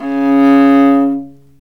Index of /90_sSampleCDs/Roland L-CD702/VOL-1/STR_Viola Solo/STR_Vla3 Arco nv
STR VIOLA 00.wav